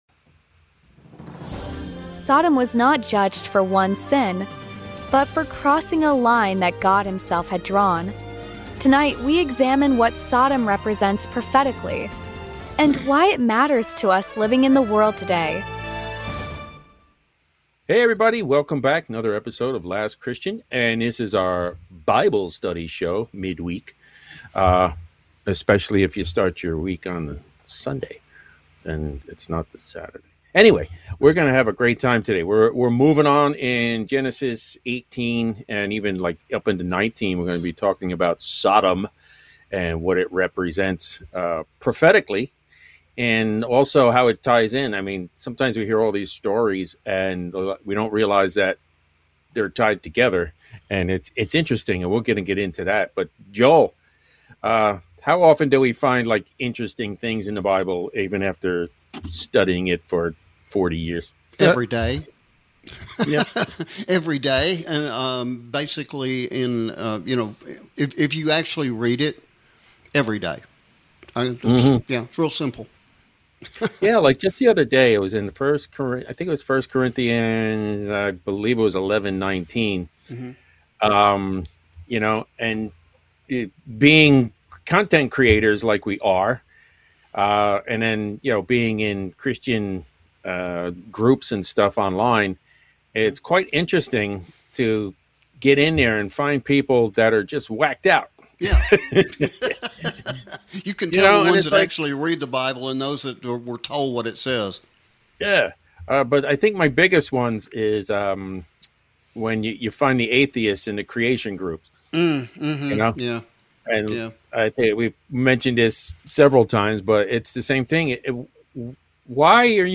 Weekly Comprehensive Bible Study